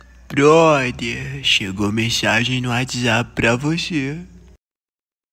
Categoria: Sons virais